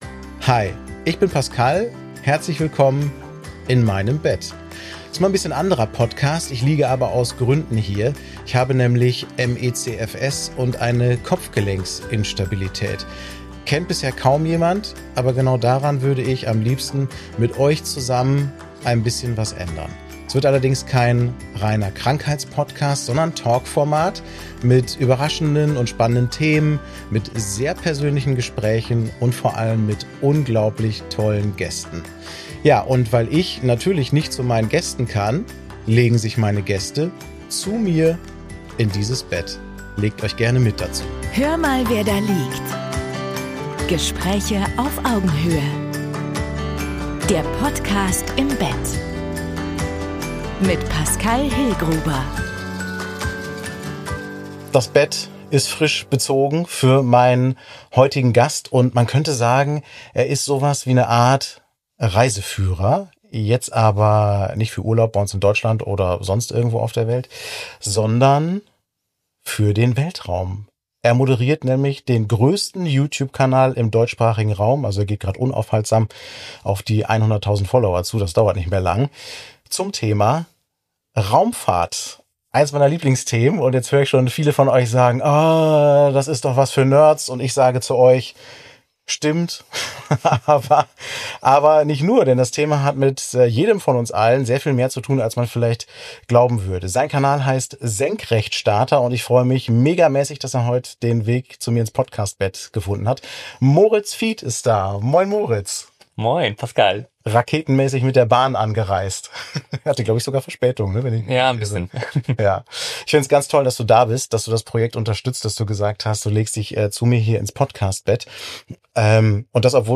Ein Gespräch zwischen Vision und Realität, voller Begeisterung für den Blick über den Tellerrand hinaus.